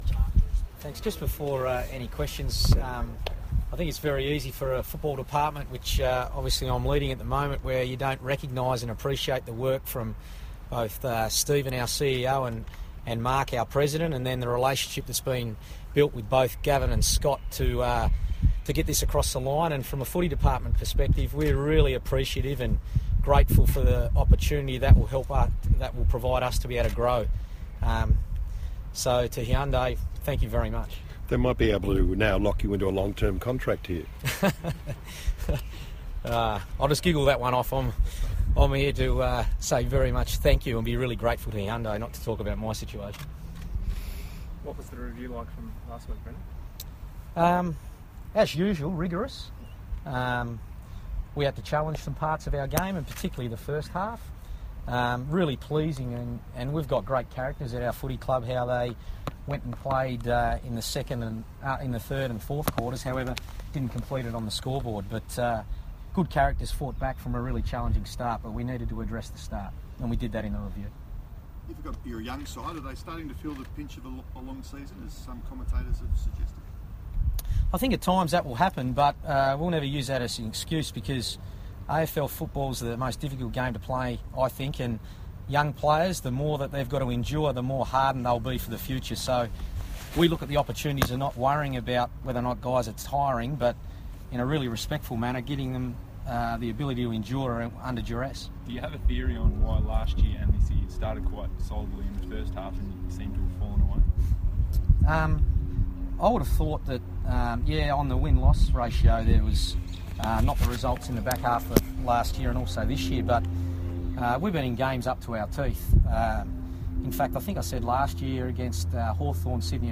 Brendon Bolton press conference - July 28